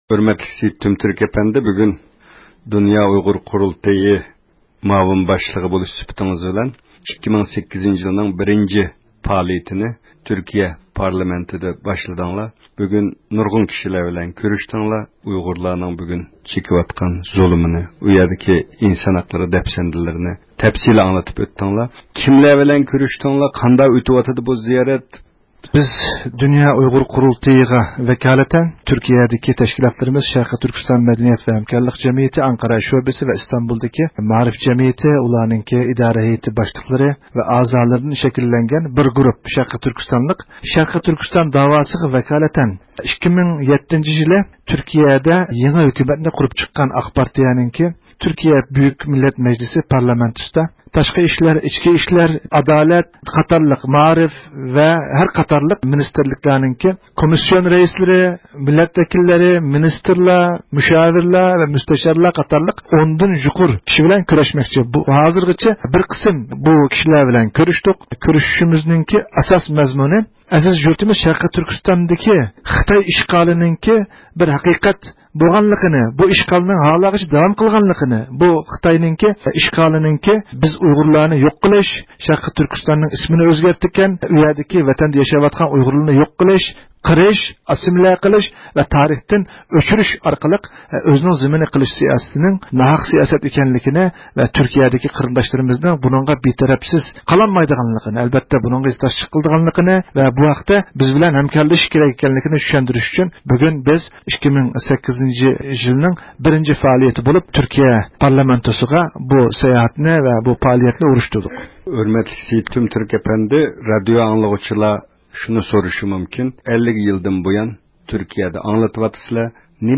تۈركىيە پارلامېنت بىناسى ئىچىدە